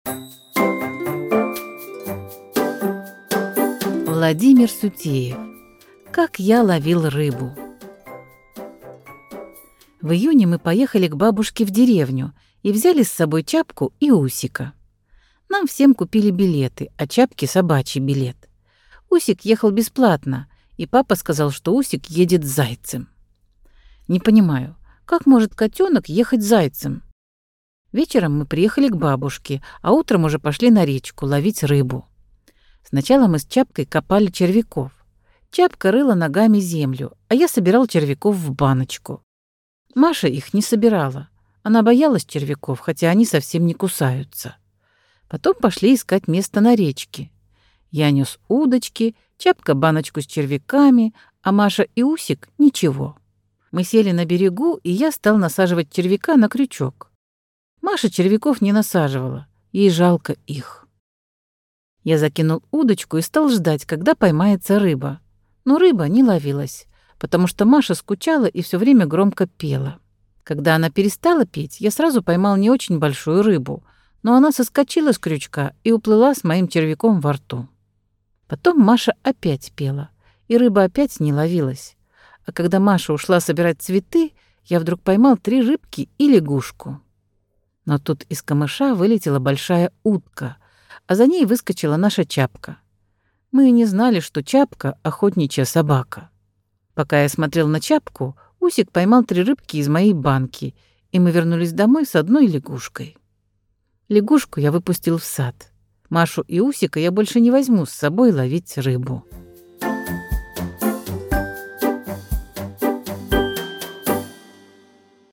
Аудиосказка «Как я ловил рыбу»